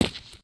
test_asphalt02.ogg